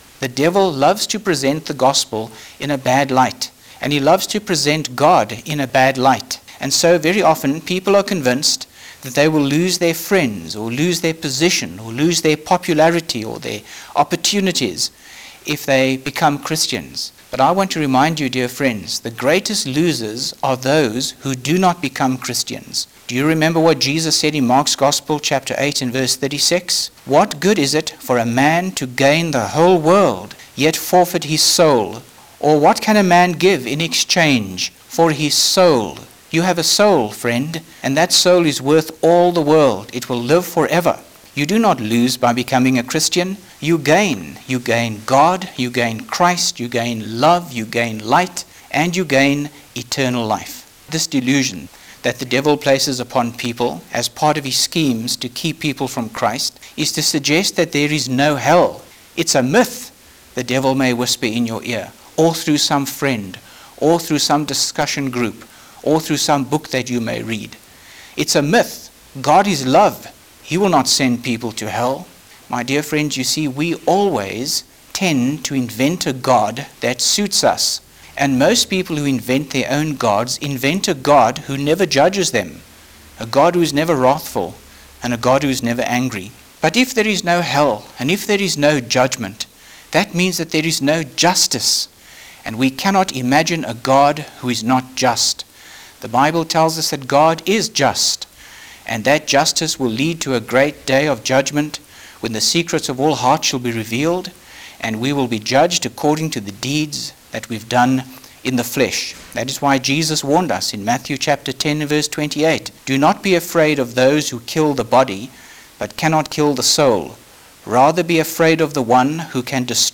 Five minute talk